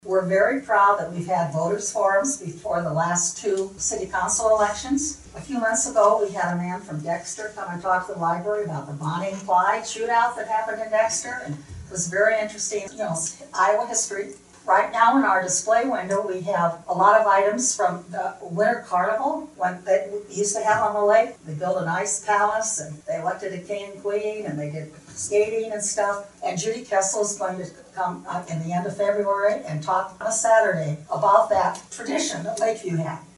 📖 The Lake View Public Library shared its annual report during the City of Lake View City Council meeting this past Monday.